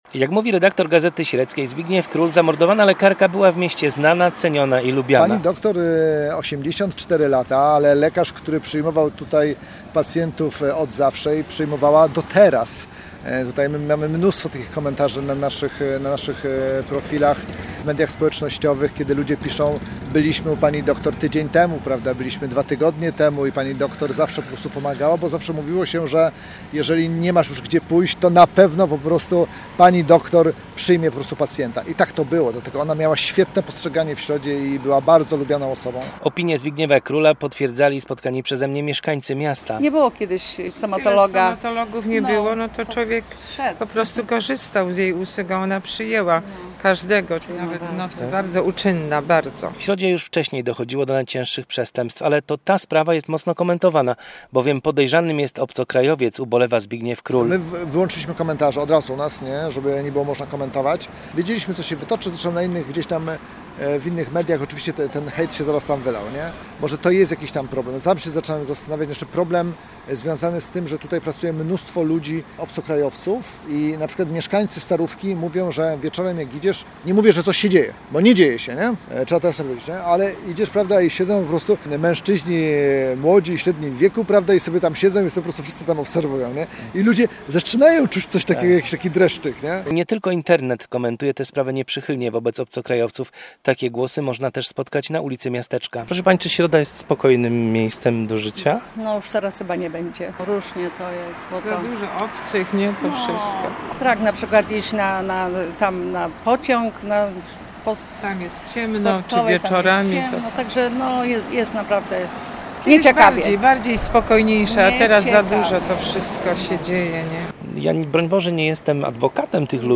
- dodają mieszkańcy Środy.
- komentują mieszkańcy miejscowości.